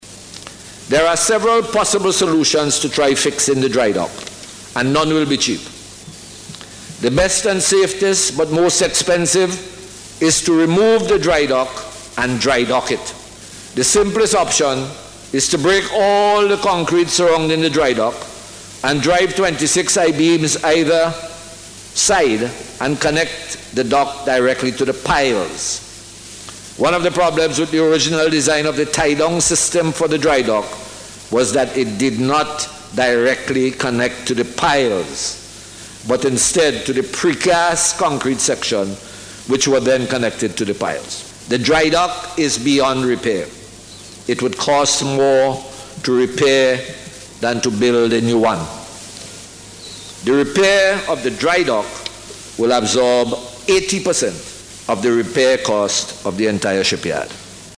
He spoke on the issue, as he responded to a question from the opposition in Parliament this week.